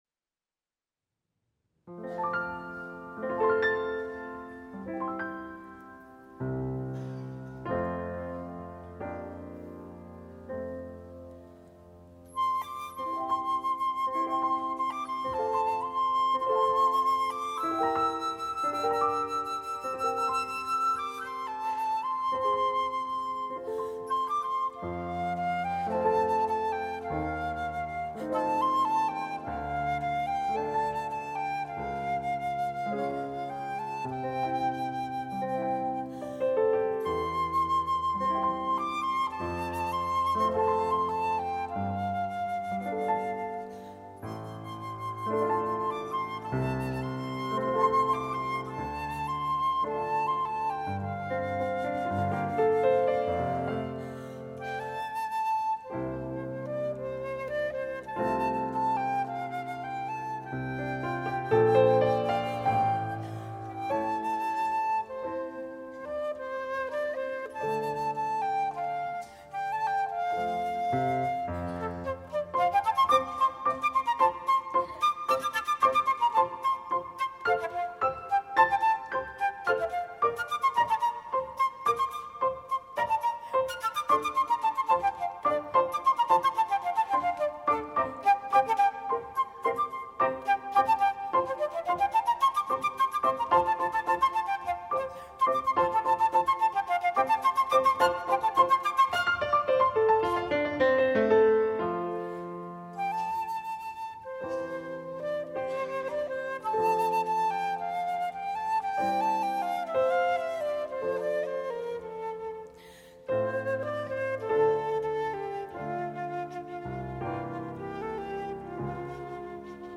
cho flûte solo và piano